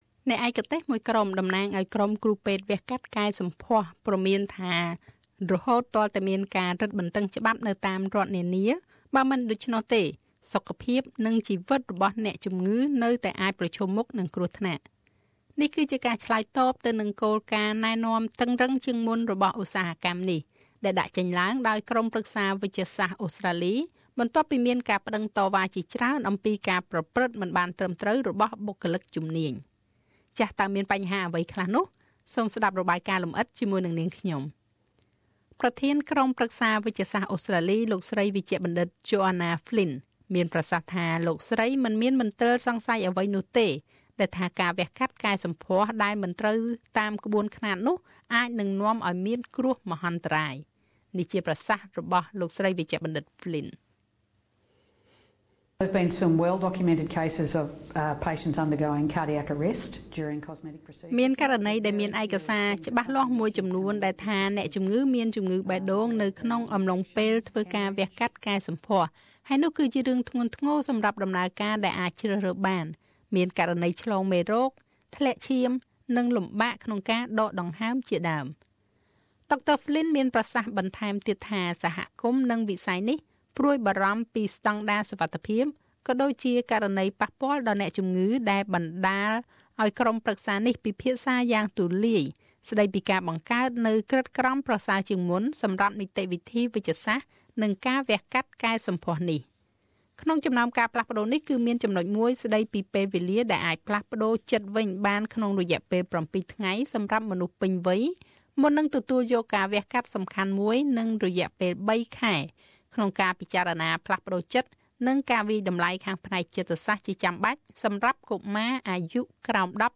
តើមានបញ្ហាអ្វីខ្លះនោះ?សូមស្តាប់របាយការណ៍លំអិត